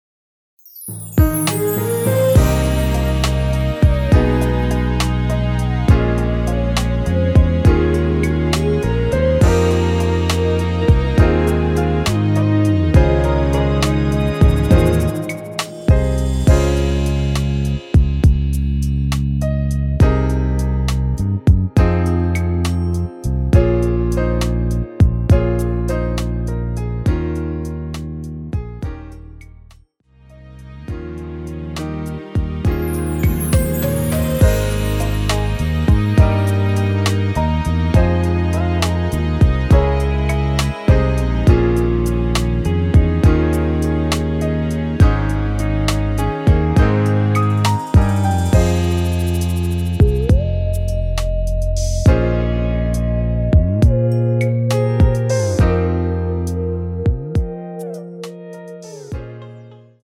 원키에서(+4)올린 MR입니다.
대부분의 여성분이 부르실수 있는 키로 제작 하였습니다.
Ab
앞부분30초, 뒷부분30초씩 편집해서 올려 드리고 있습니다.